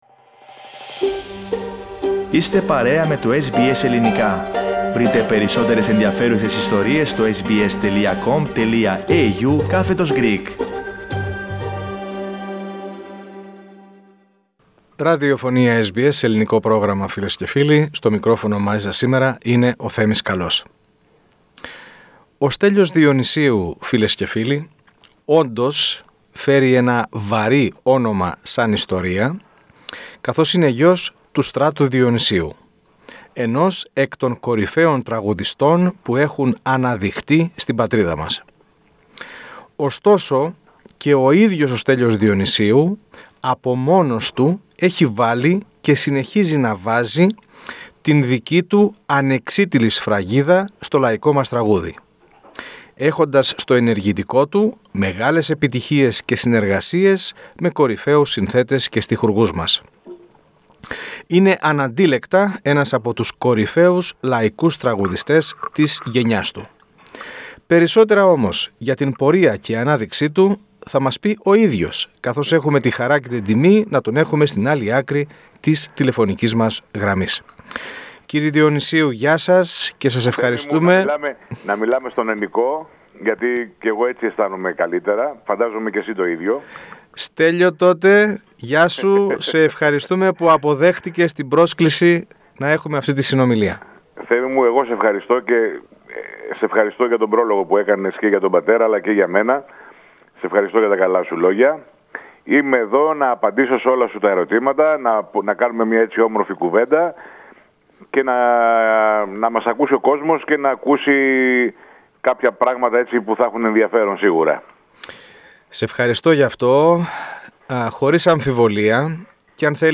Τον λαϊκό τραγουδιστή φιλοξένησε το Ελληνικό Πρόγραμμα της ραδιοφωνίας SBS (SBS Greek) σε μία μακρά και εκ βαθέων συνέντευξη.